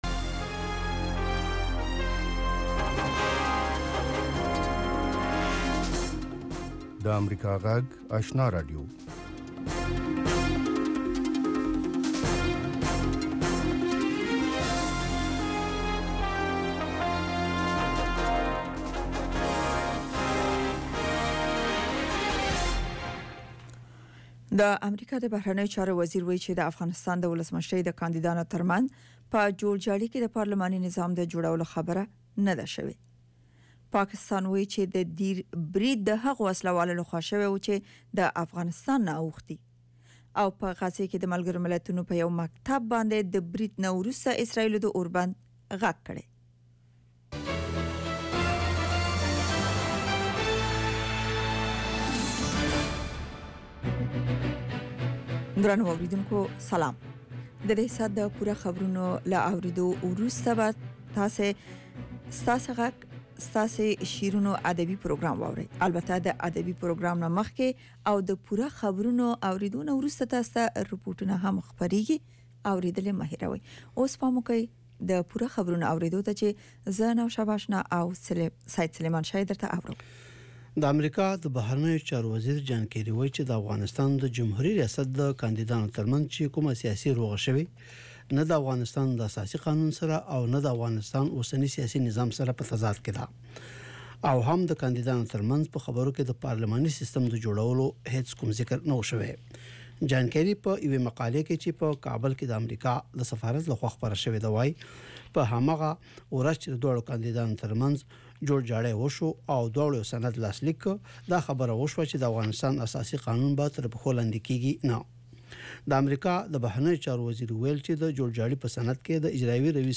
یو ساعته خپرونه: تازه خبرونه، د ځوانانو، میرمنو، روغتیا، ستاسو غږ، ساینس او ټیکنالوژي، سندرو او ادب په هکله اونیز پروگرامونه.